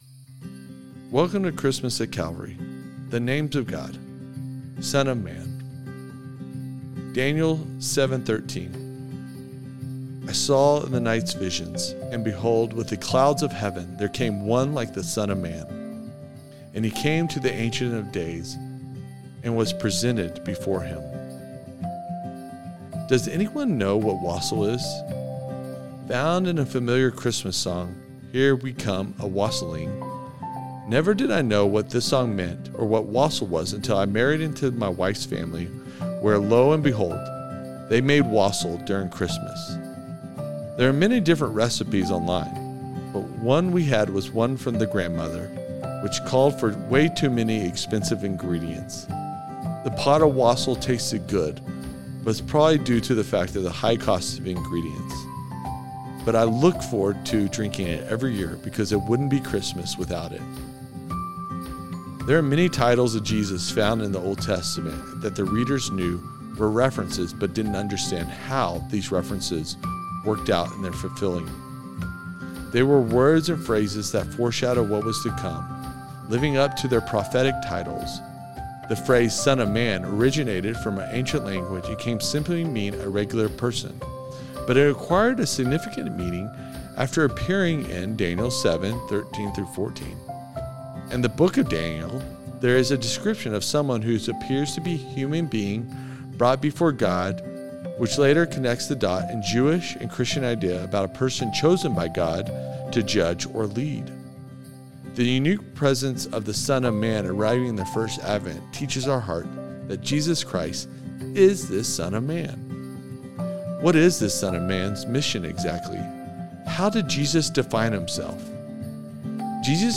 Advent Readings & Prayer